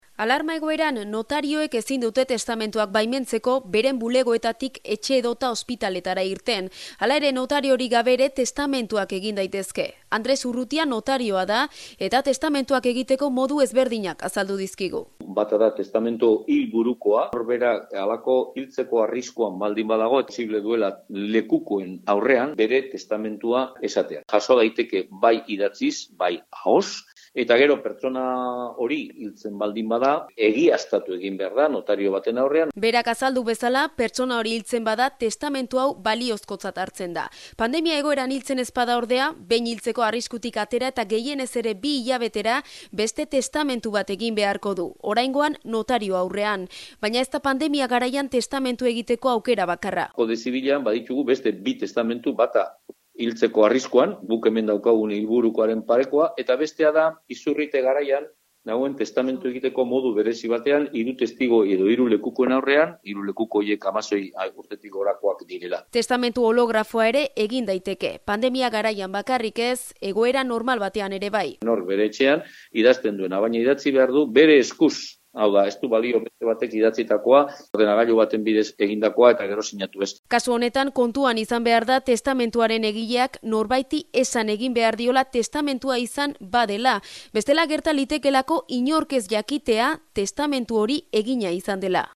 Elkarrizketa, irratiko esteka honetan: